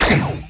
laser_04.wav